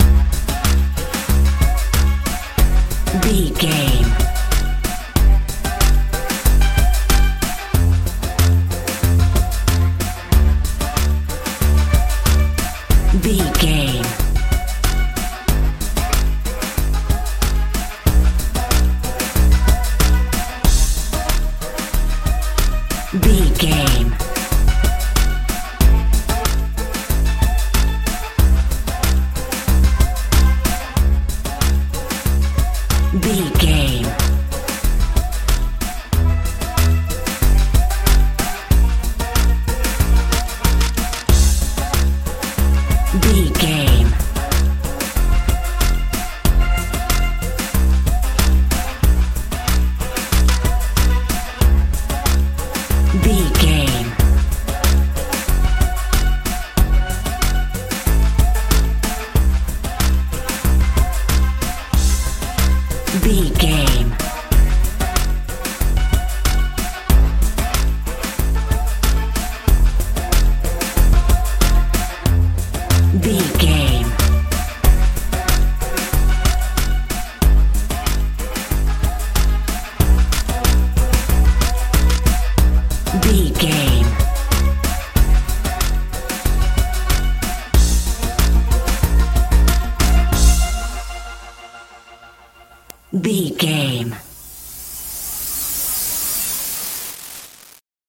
hip hop feel
Ionian/Major
C♯
mystical
strange
synthesiser
bass guitar
drums
groovy